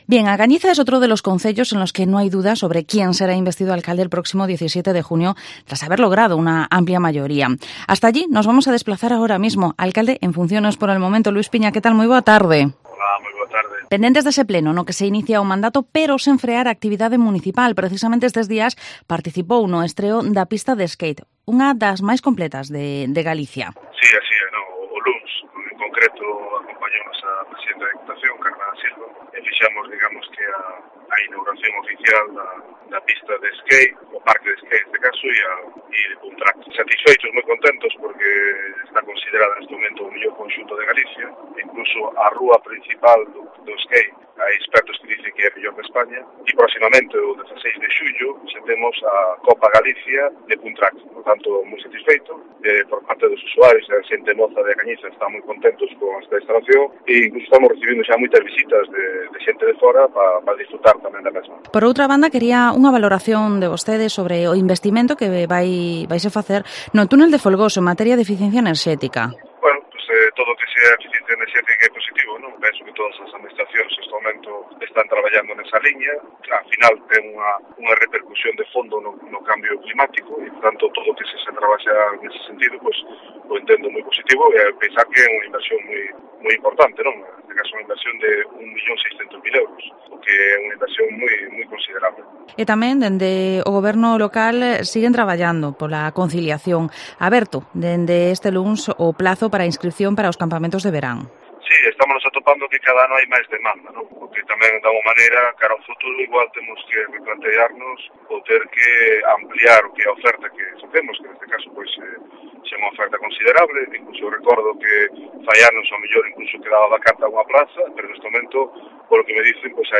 Entrevista al Alcalde en funciones de A Cañiza, Luis Piña